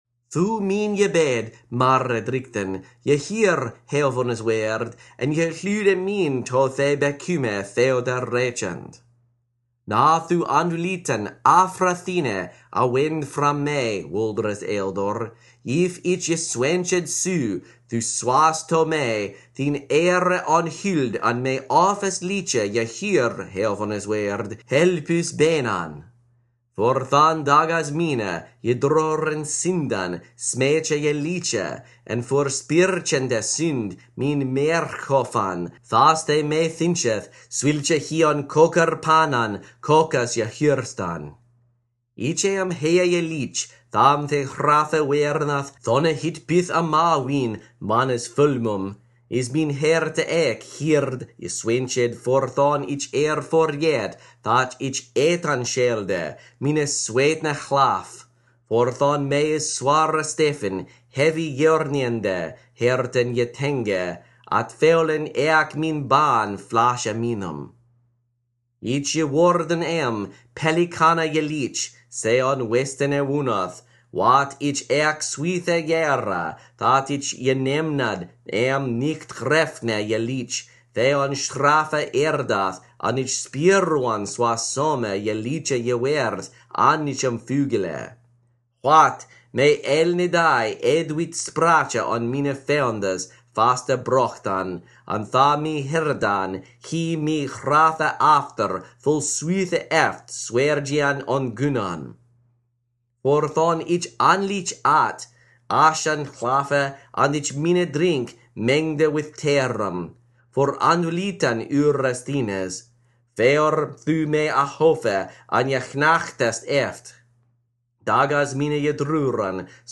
asprparispsalter-psalm101-all-spoken.mp3